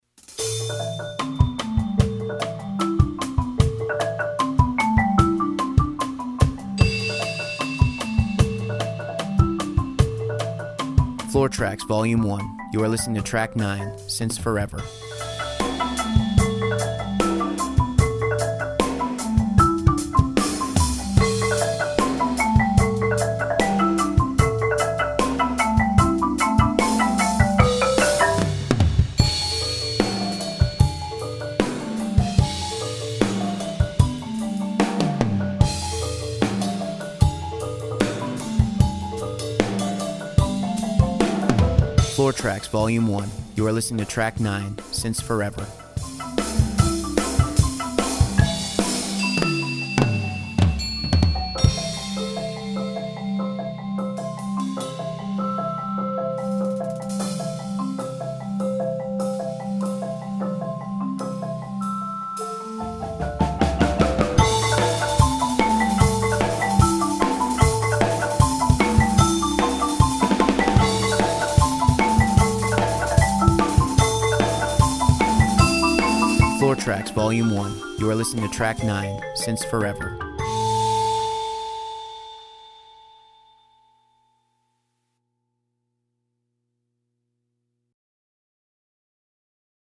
(with voiceover)   Purchase high-quality track